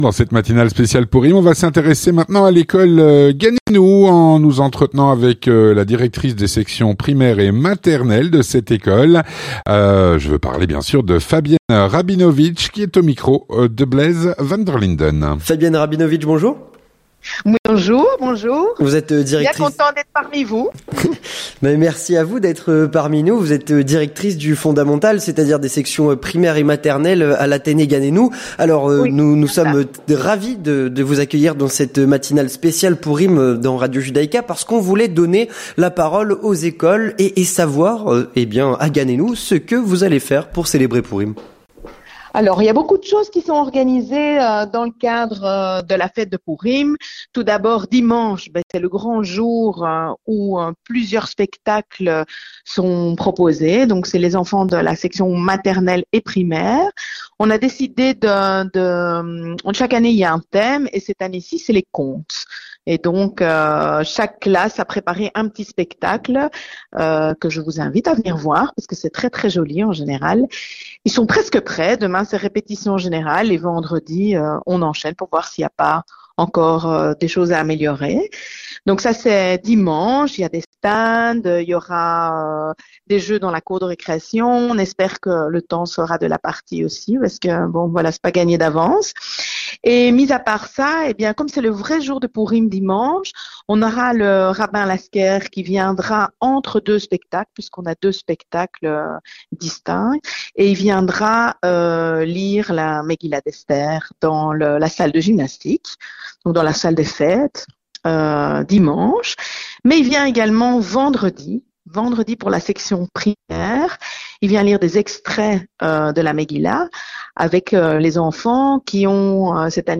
4. Rencontre - Pourim à Ganenou.